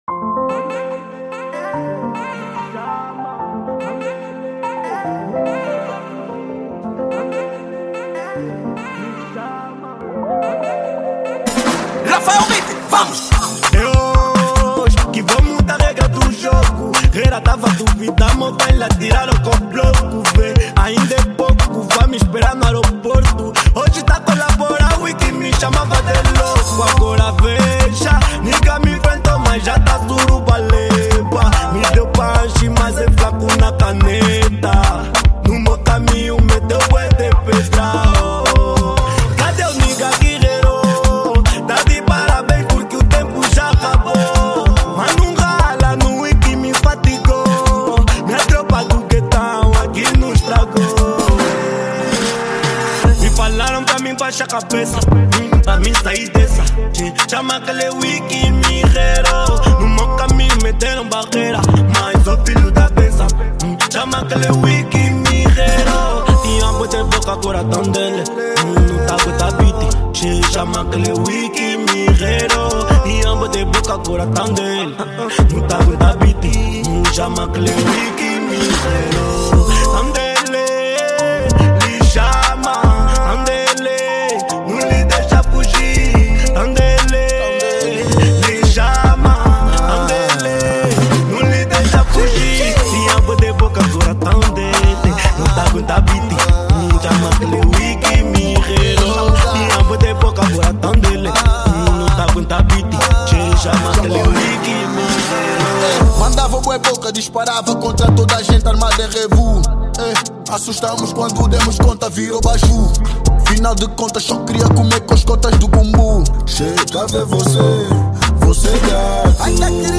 Genero: Kuduro